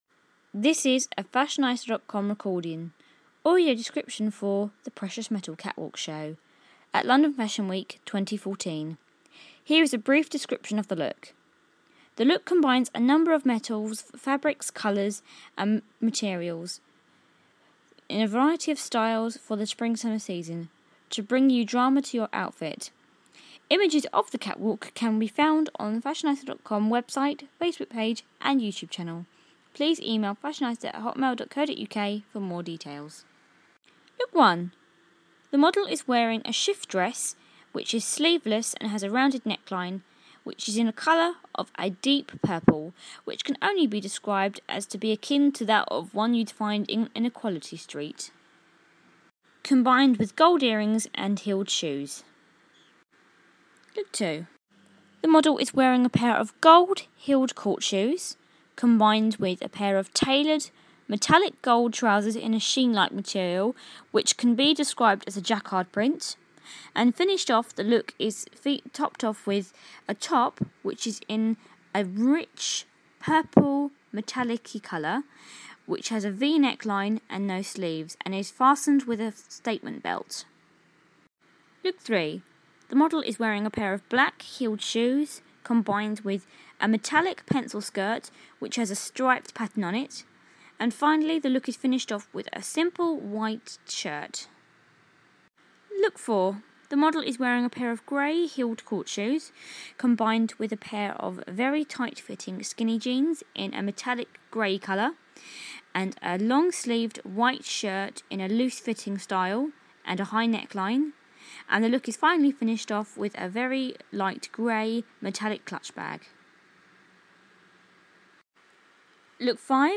Audio Description for The Precious Metals Catwalk Show